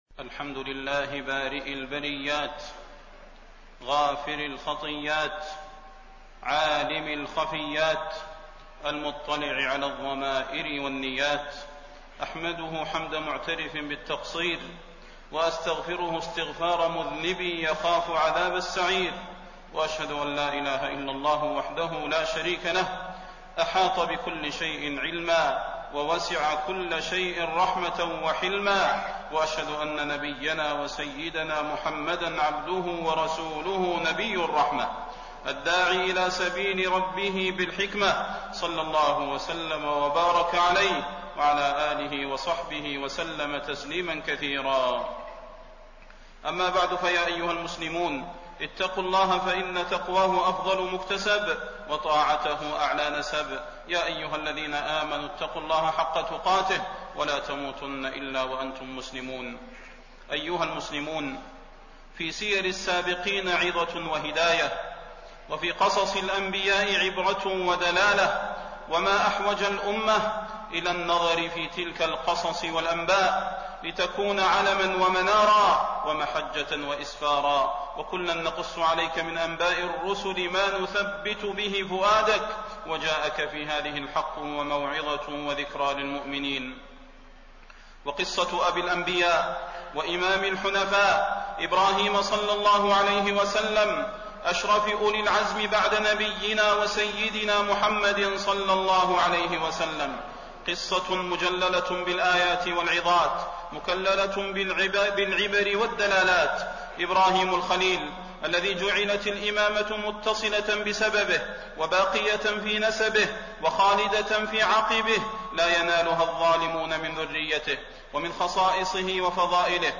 تاريخ النشر ١٦ ذو القعدة ١٤٣٢ هـ المكان: المسجد النبوي الشيخ: فضيلة الشيخ د. صلاح بن محمد البدير فضيلة الشيخ د. صلاح بن محمد البدير قصة إبراهيم وابنه إسماعيل